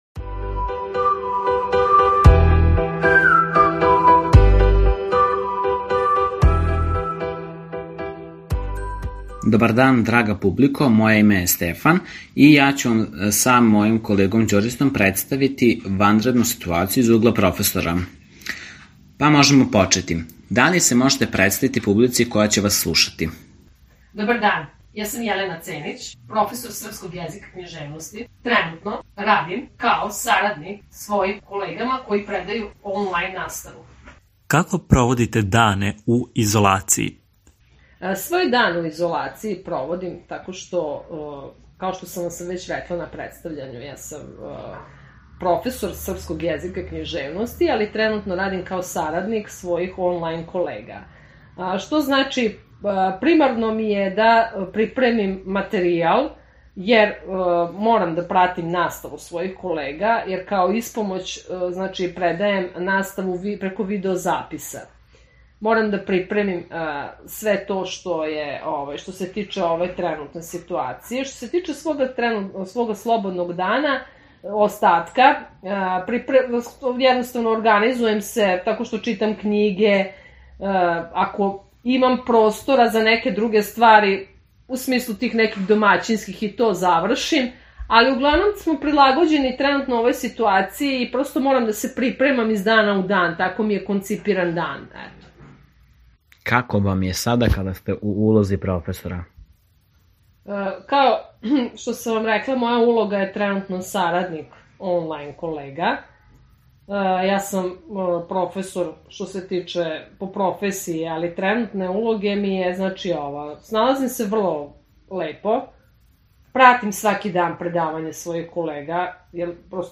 Ovog puta, intervju smo radili u audio formatu, a sa kim smo sve prethodno razgovarali možete pogledati na našoj YouTube stranici i sajtu.